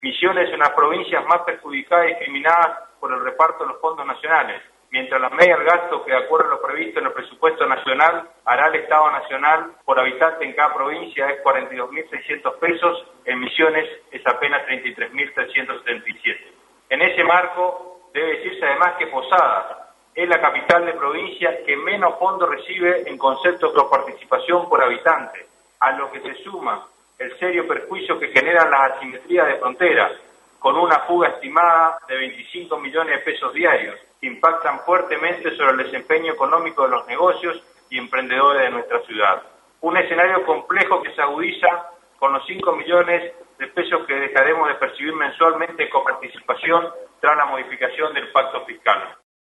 Al tiempo que subrayó las dificultades económicas que atraviesa la Municipalidad de Posadas, la que menor porcentaje de coparticipación recibe en relación a otras capitales de provincia y su número de habitantes, el intendente Joaquín Losada explicó ayer durante casi una hora distintos aspectos de su gestión en la apertura de sesiones ordinarias 2018 del Concejo Deliberante capitalino.